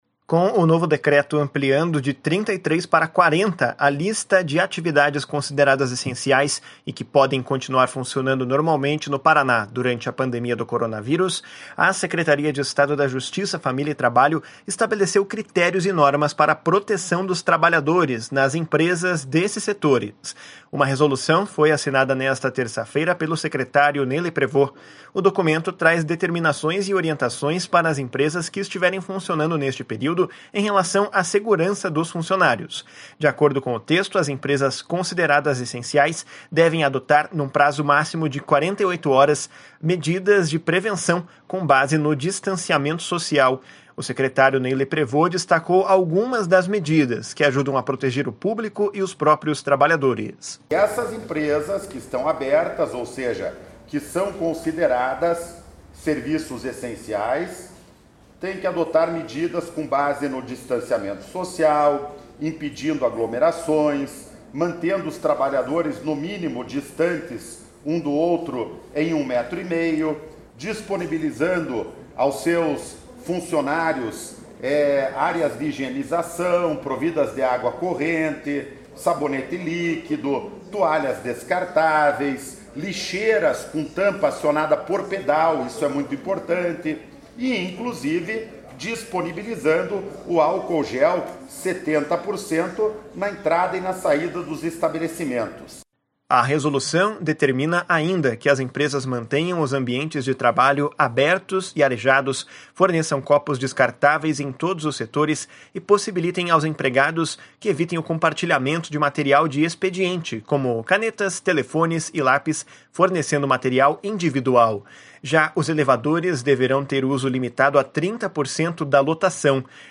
De acordo com o texto, as empresas consideradas essenciais devem adotar num prazo máximo de 48 horas, medidas de prevenção, com base no distanciamento social. O secretário Ney Leprevost destacou algumas das medidas, que ajudam a proteger o público e os próprios trabalhadores. // SONORA NEY LEPREVOST //